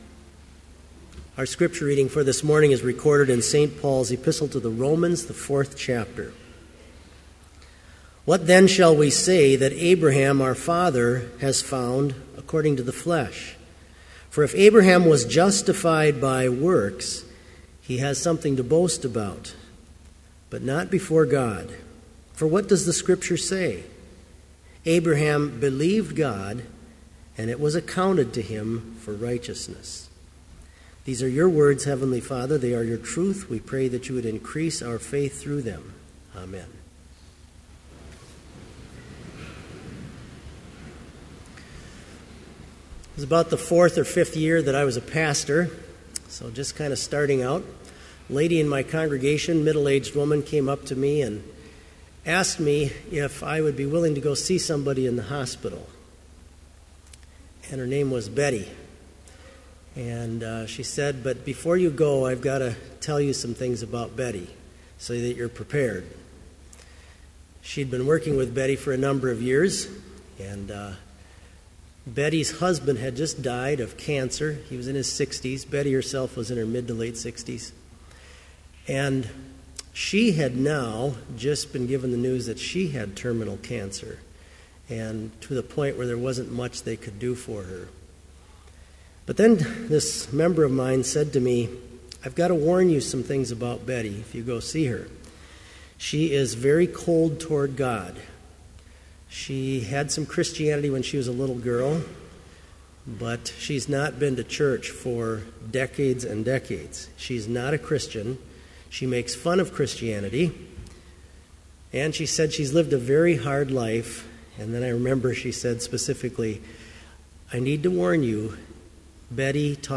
Complete Service
• Hymn 226, vv. 1, 3 & 4, By Grace I'm Saved
• Homily
This Chapel Service was held in Trinity Chapel at Bethany Lutheran College on Wednesday, February 19, 2014, at 10 a.m. Page and hymn numbers are from the Evangelical Lutheran Hymnary.